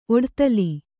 ♪ oḍtali